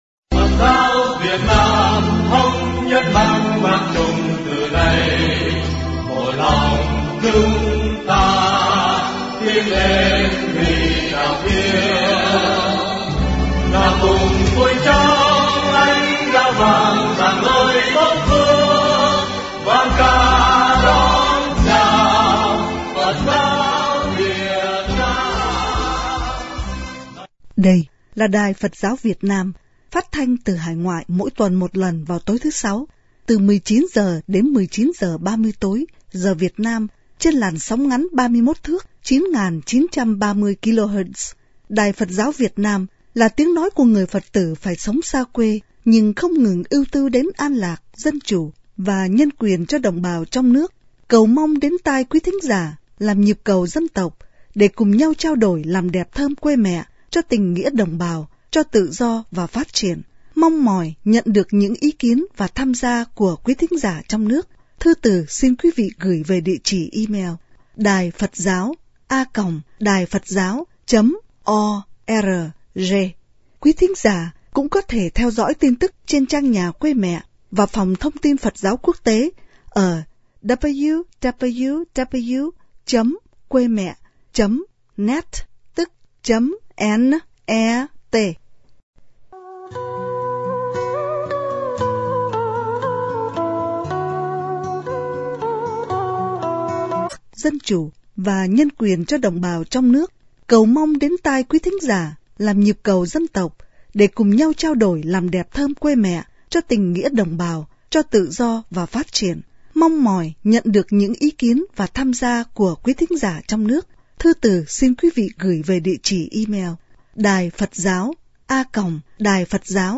cuộc phỏng vấn đặc biệt